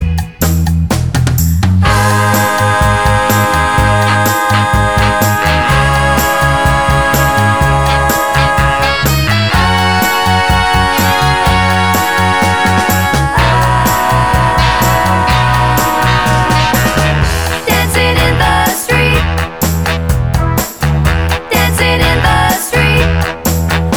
(With BVs) - Two Semitones Up